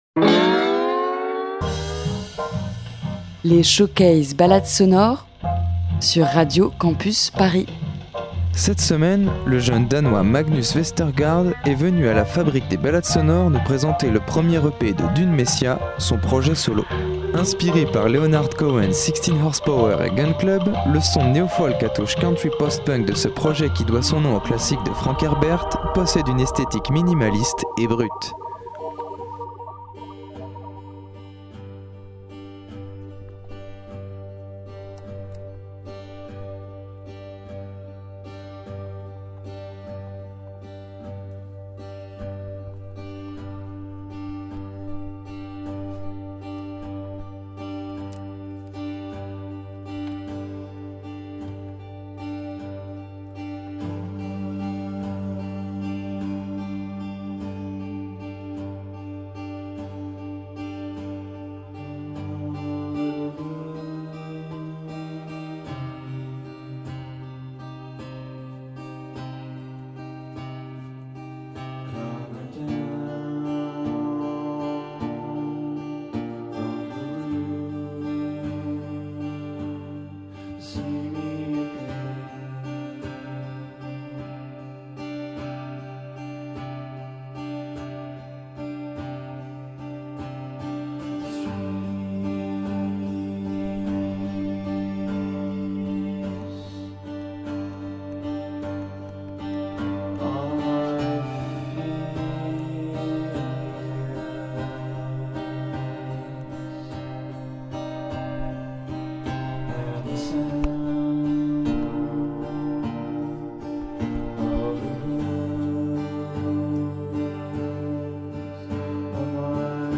neofolk
esthétique minimaliste et brute